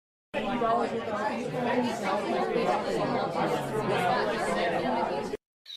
MKtsZsuLVgg_Sonido-de-gente-hablando-sonido-ambiente-dPinqpS5pNM.mp3